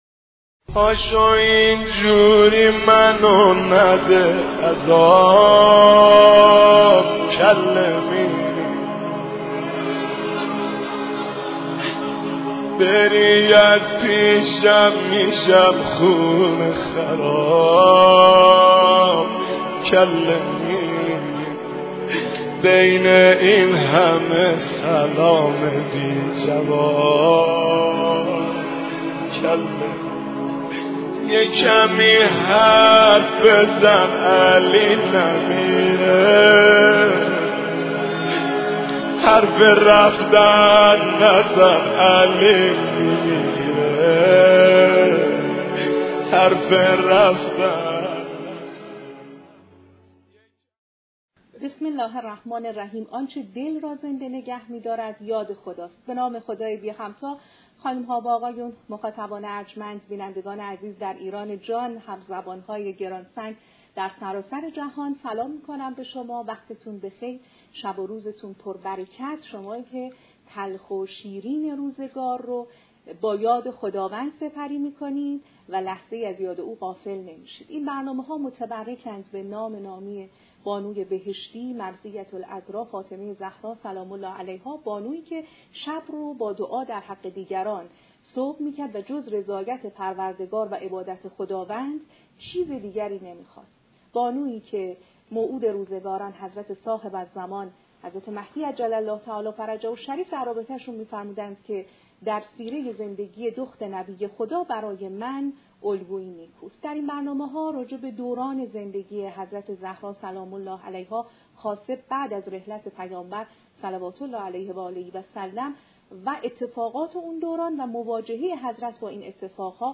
پایان برنامه با شعری در فضیلت حضرت زهرا (س) و دعایی برای بینندگان همراه است.